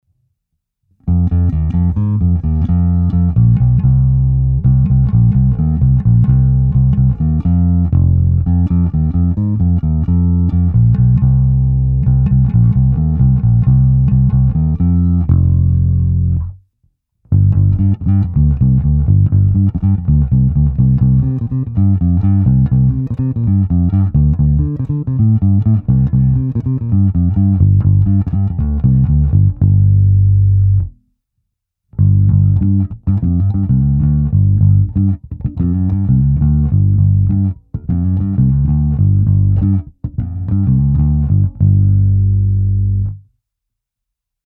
Zvuk je typický Precision.
Vliv tónové clony – jednotlivé části nahrávky jsou: plně otevřená tónová clona – mírně stažená – stažená skoro úplně – úplně zavřená tónová clona.